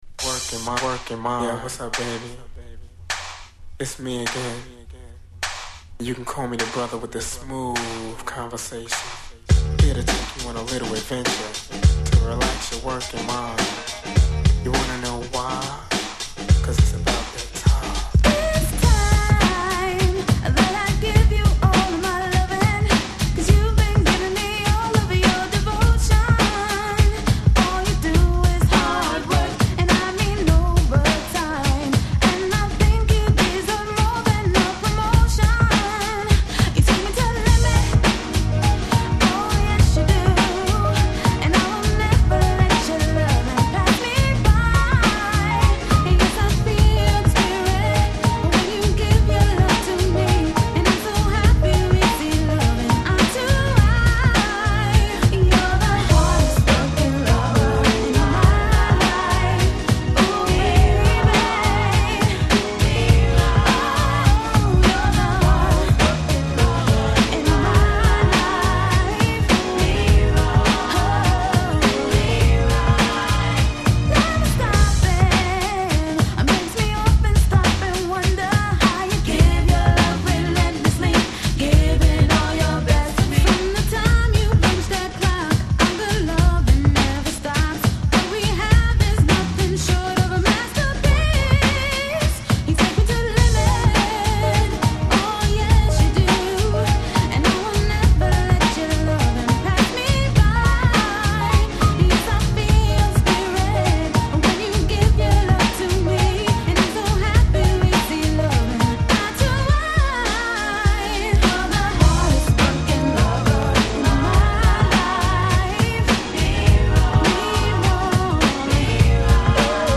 Genre: #R&B